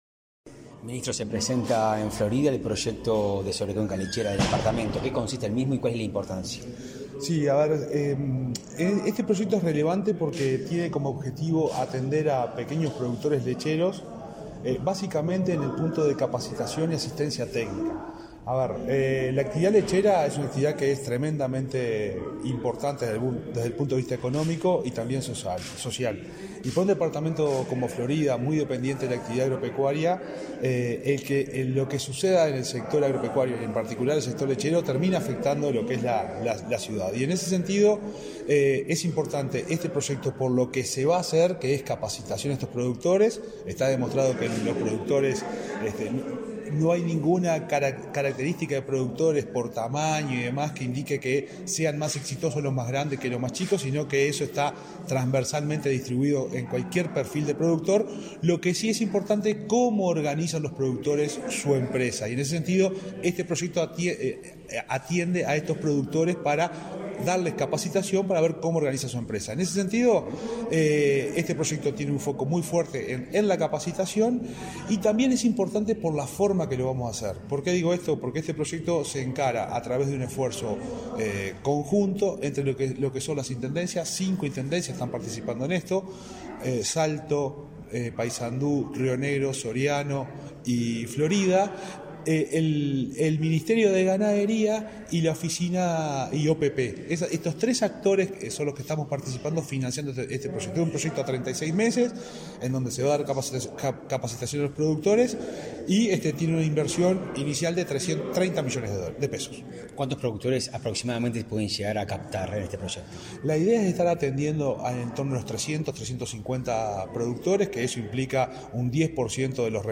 Entrevista al ministro interino de Ganadería, Agricultura y Pesca, Juan Ignacio Buffa